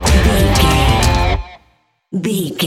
Ionian/Major
electric guitar
drums